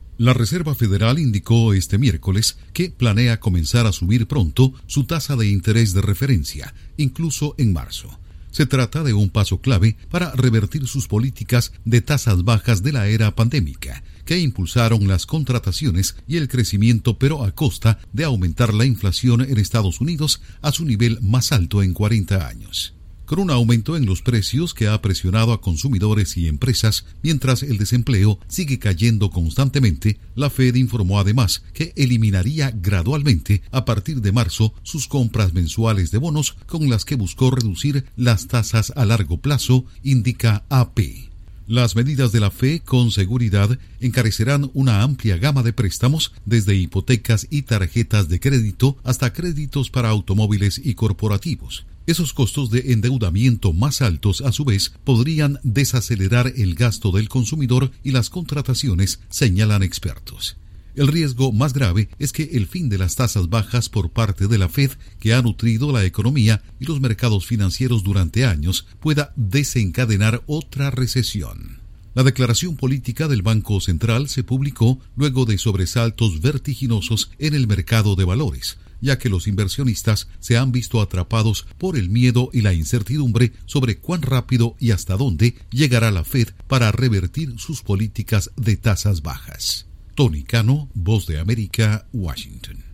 Reserva Federal de EE.UU. planea subir las tasas de interés pronto para frenar inflación. Informa desde la Voz de América en Washington